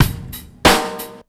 Ghetto Straight 93bpm.wav